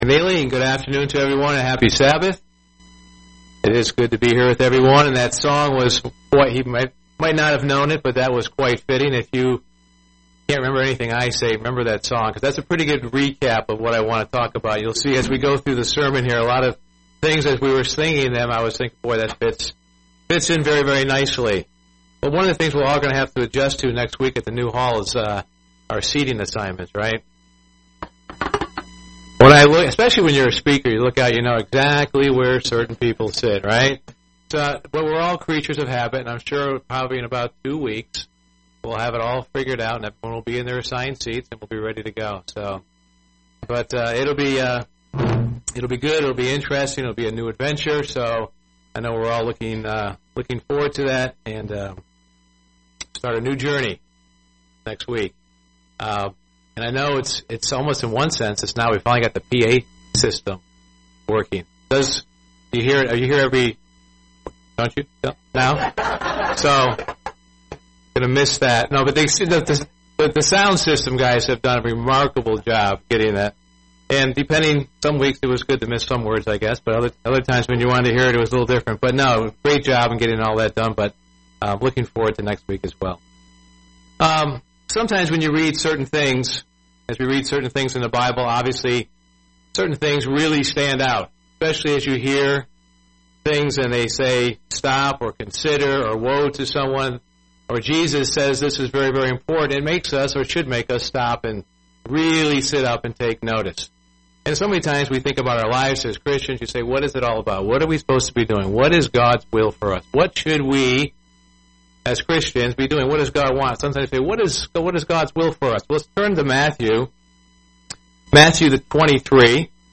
UCG Sermon Notes Notes: Matt. 23:23-25 --> Jesus calling the Parise having an outward ministry.